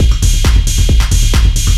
DS 135-BPM B7.wav